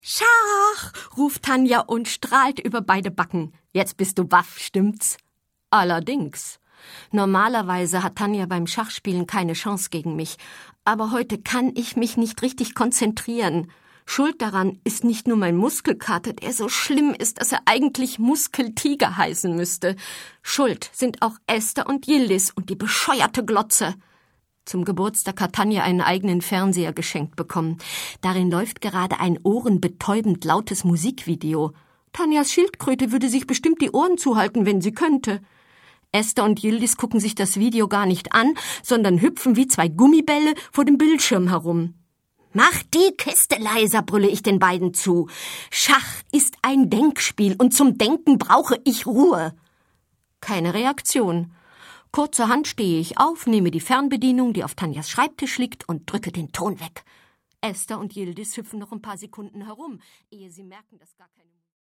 Schlagworte Audio-CD • AUDIO/Kinder- und Jugendbücher/Erstlesealter, Vorschulalter • AUDIO/Kinder- und Jugendbücher/Kinderbücher bis 11 Jahre • Breakdance • Freundschafr • Hörbücher • Hörbuch für Kinder/Jugendliche • Hörbuch für Kinder/Jugendliche (Audio-CD) • Hörbuch; Lesung für Kinder/Jugendliche • Kinder-CDs (Audio) • Mädchen • Mädchen; Kinder-/Jugendlit.